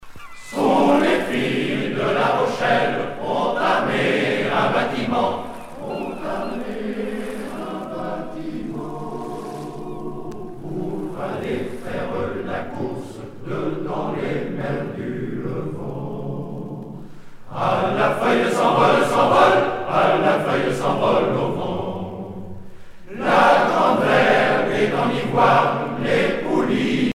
Genre laisse
Ensemble choral